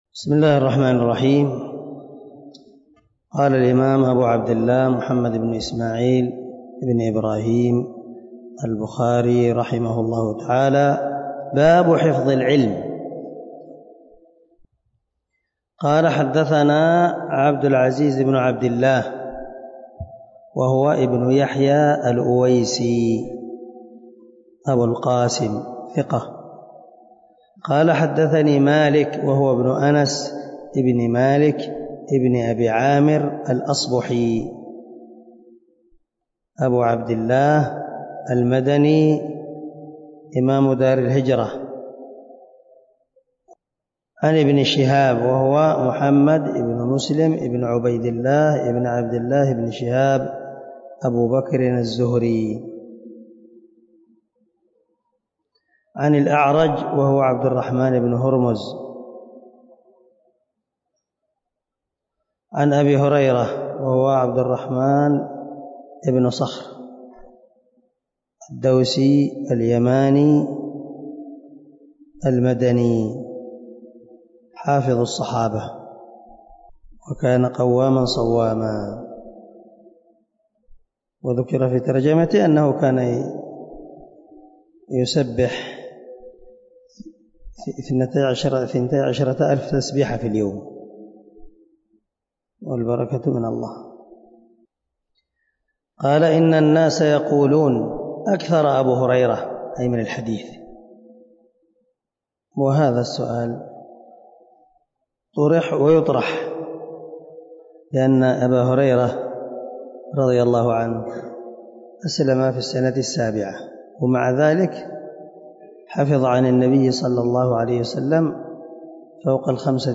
111الدرس 56 من شرح كتاب العلم حديث رقم ( 118 ) من صحيح البخاري
دار الحديث- المَحاوِلة- الصبيحة.